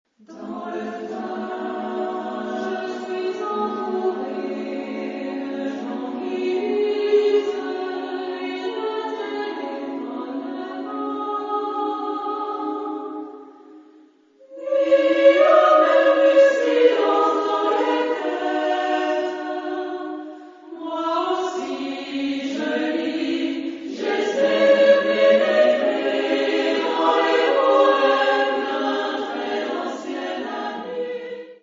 Genre-Style-Forme : Profane ; Poème ; contemporain
Type de choeur : SMA  (3 voix égales de femmes )
Tonalité : do dièse mineur